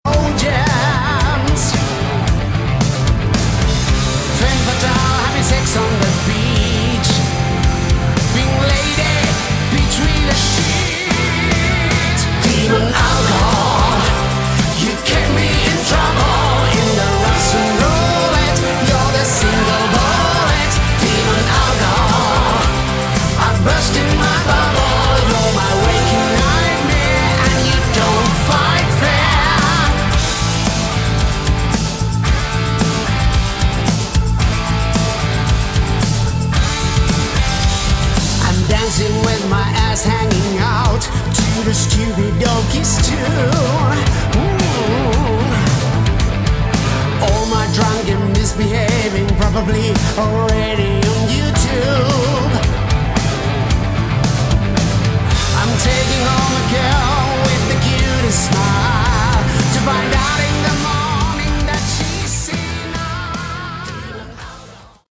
Category: Hard Rock
vocals
guitars, keyboards, programming
organ
bass
drums